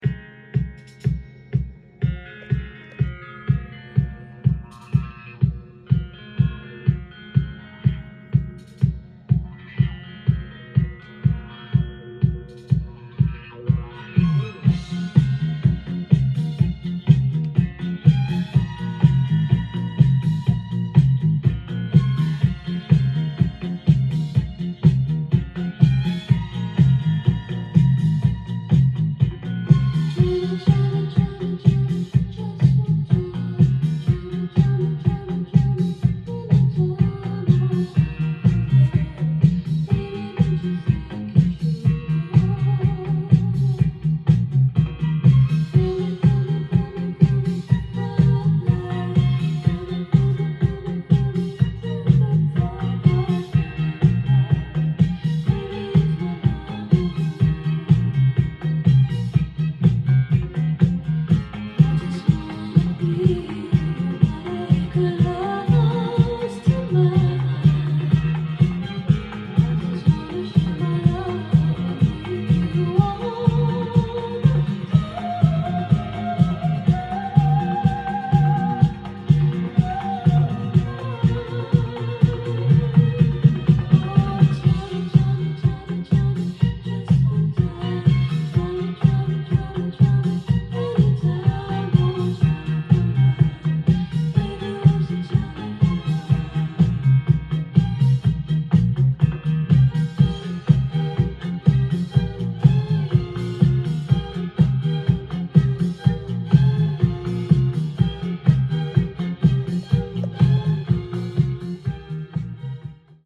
店頭で録音した音源の為、多少の外部音や音質の悪さはございますが、サンプルとしてご視聴ください。
ノンストップ・ディスコ・メドレー式の大作